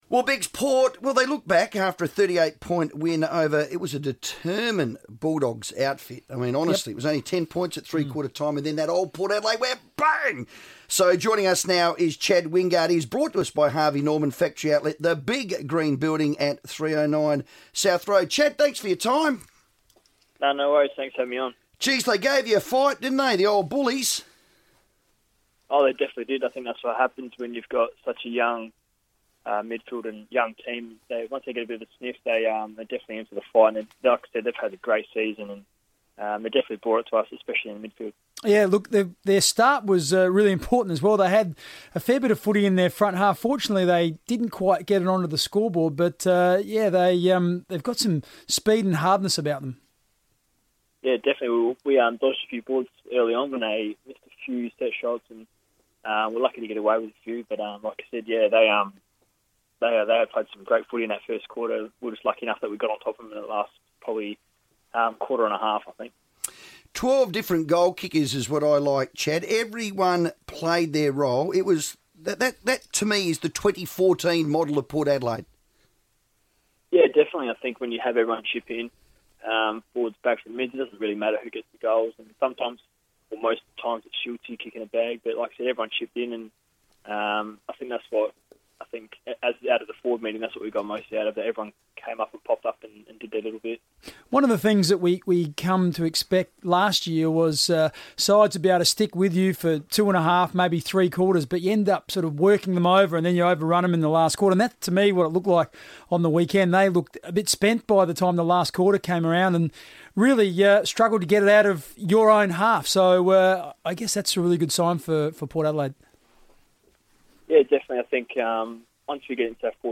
Chad Wingard speaks to the guys on FIVEaa